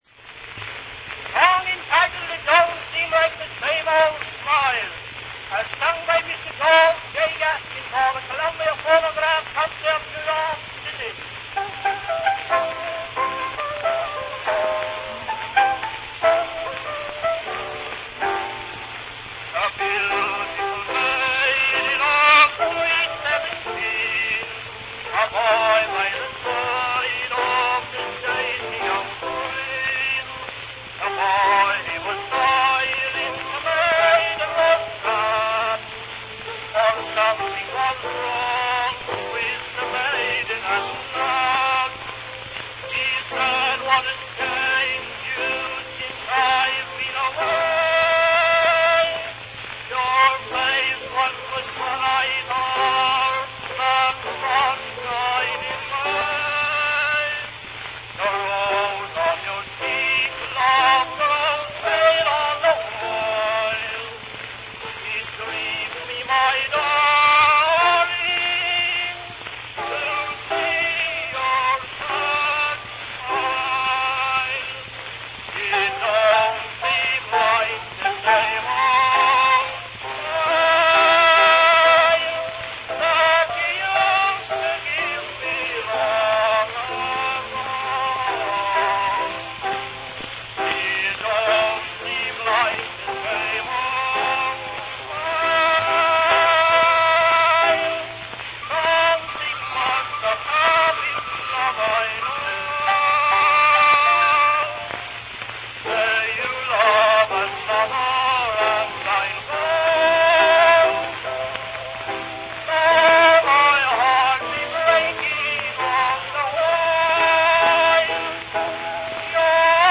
RealAudio file from a wax cylinder recording